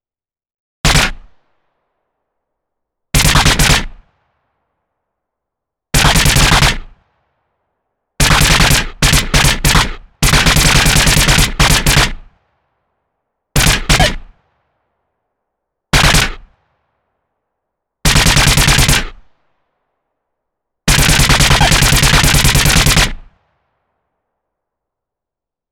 Tiếng Súng Laser bắn nhau
Thể loại: Đánh nhau, vũ khí
Description: Tiếng súng laser bắn nhau là hiệu ứng âm thanh được sử dụng phổ biến trong chỉnh sửa video, đặc biệt trong các thể loại phim khoa học viễn tưởng hoặc video game.
Tieng-sung-laser-ban-nhau-www_tiengdong_com.mp3